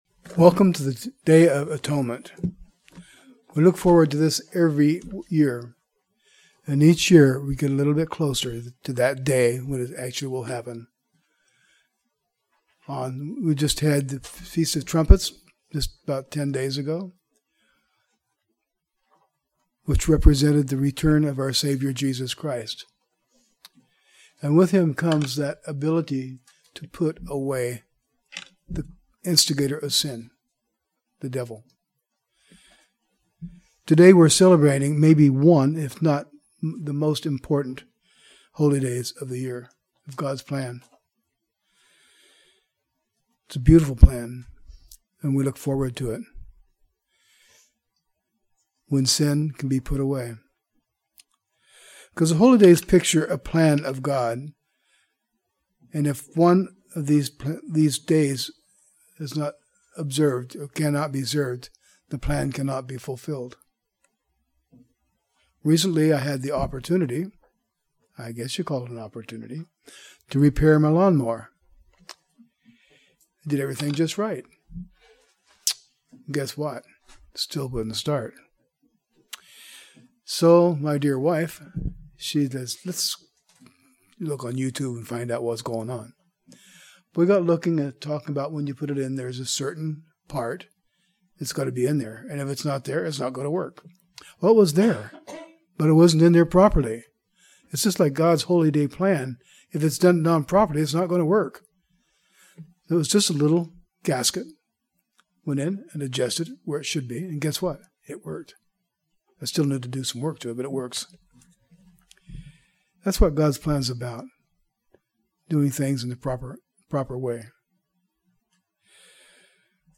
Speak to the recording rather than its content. Given in Northwest Arkansas